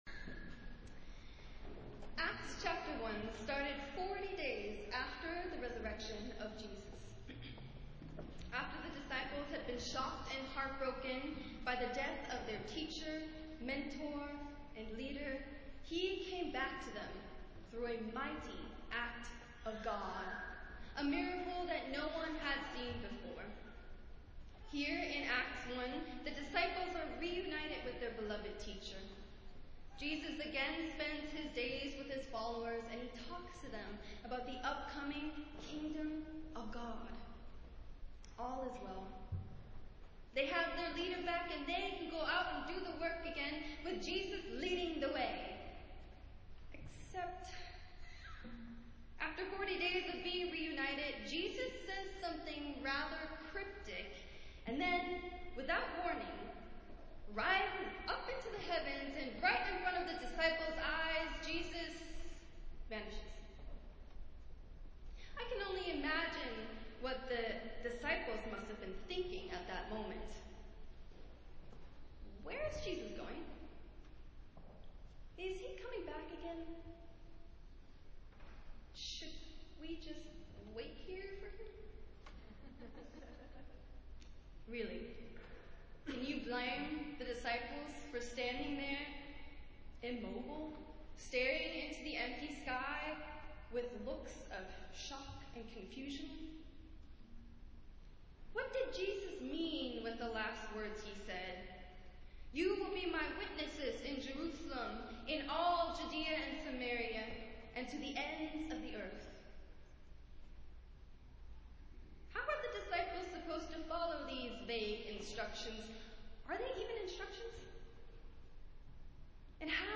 Festival Worship - Seventh Sunday after Easter